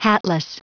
Prononciation du mot hatless en anglais (fichier audio)
Prononciation du mot : hatless